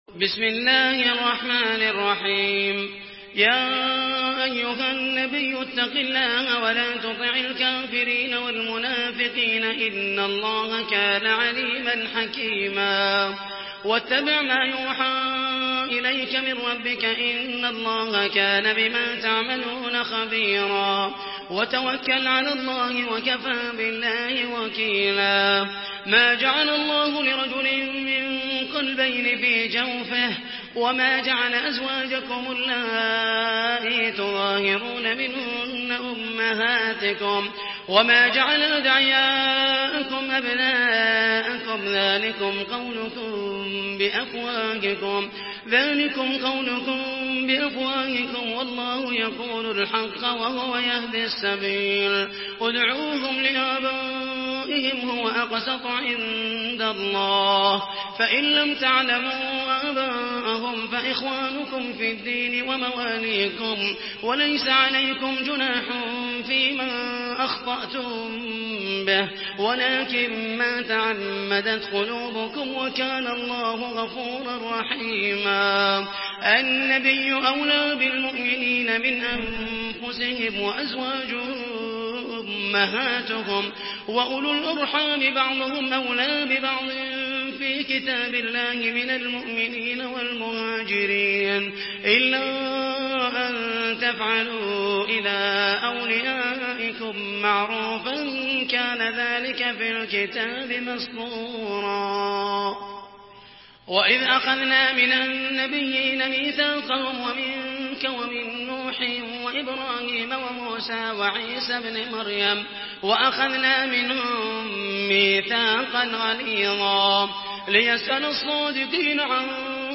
Surah الأحزاب MP3 by محمد المحيسني in حفص عن عاصم narration.
مرتل